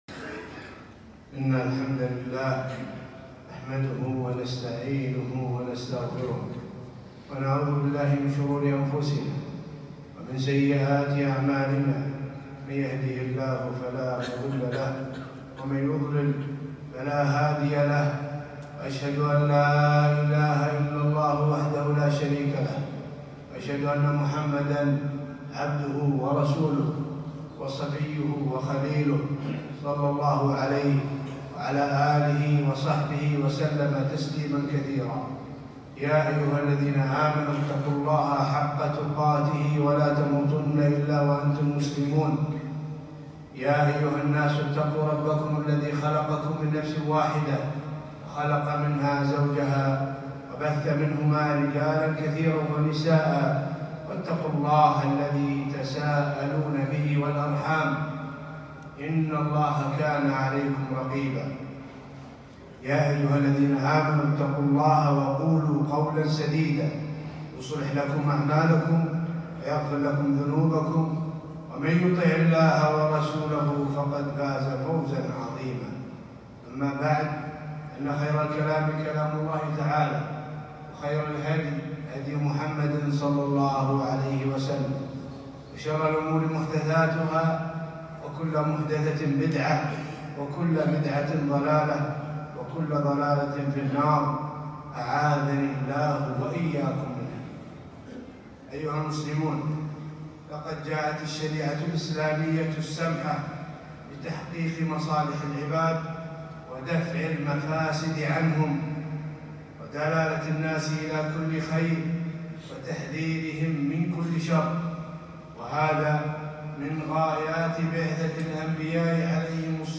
خطبة - خطورة الفساد الإداري والمالي